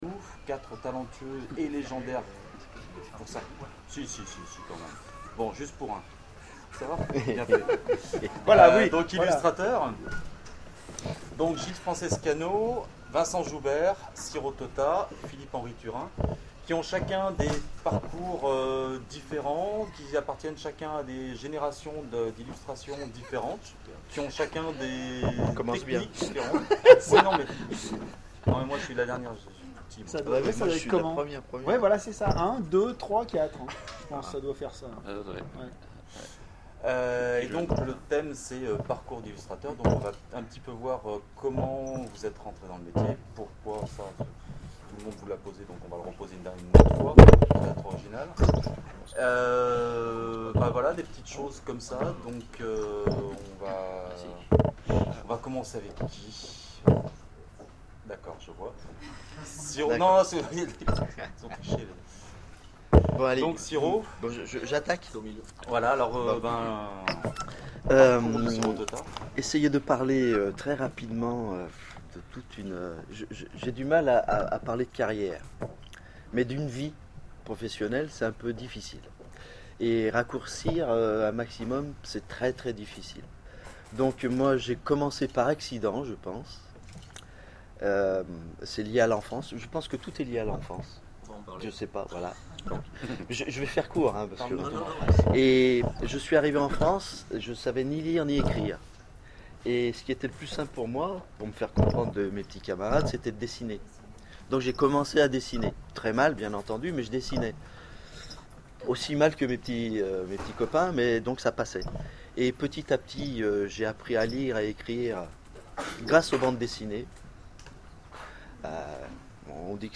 Oniriques 2013 : Conférence Parcours d'illustrateurs